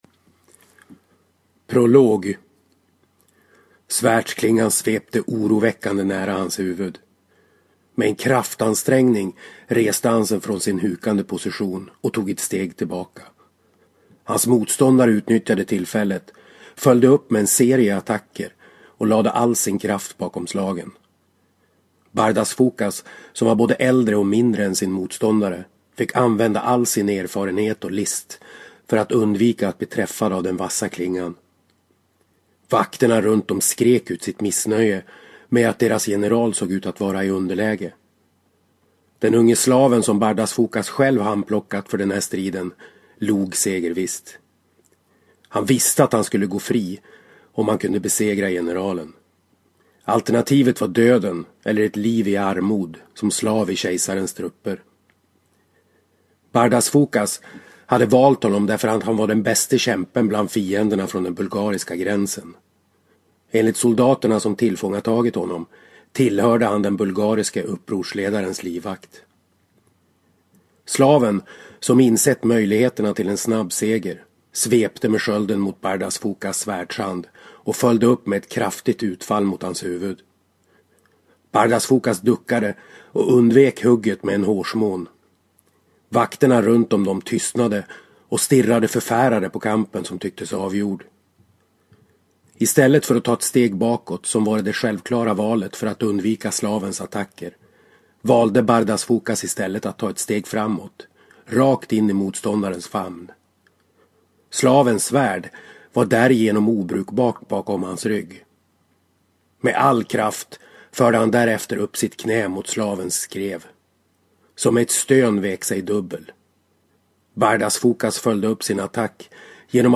Svekens tid – Ljudbok